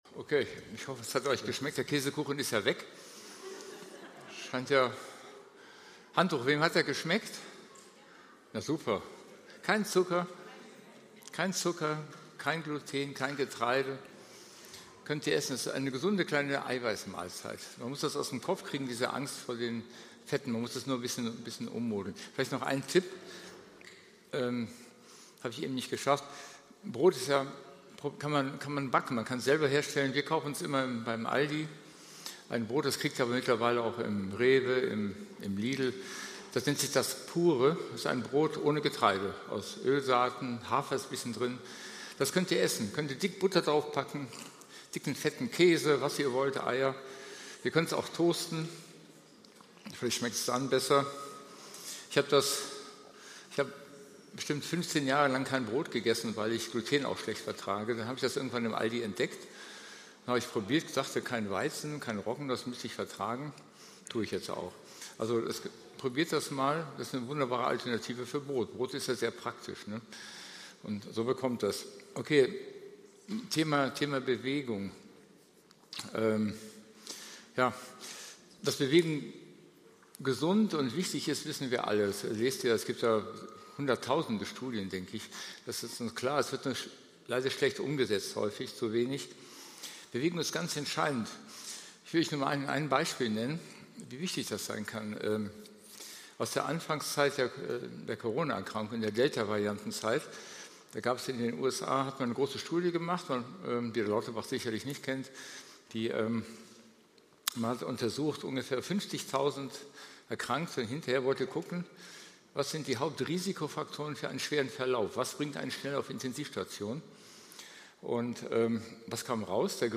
Oktober 2024 Predigt-Reihe